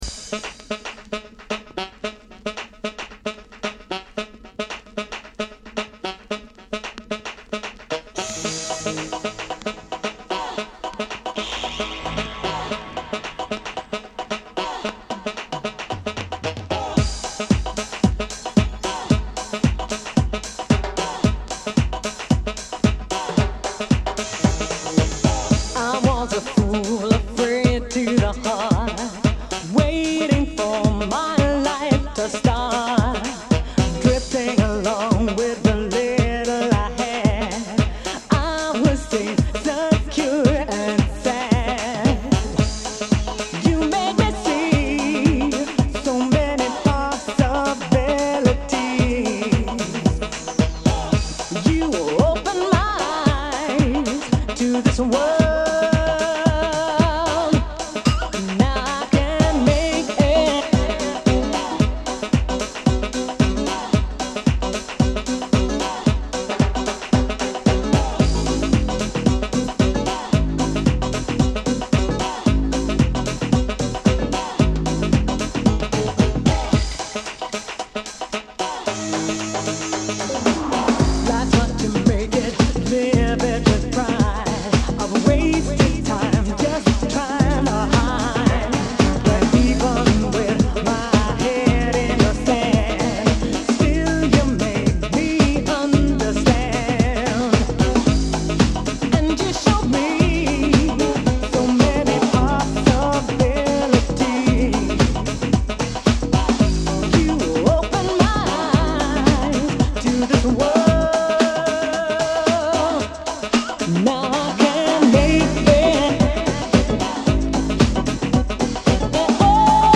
> TECHNO/HOUSE/BREAKS